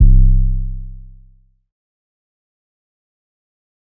Metro Mean 808 (C).wav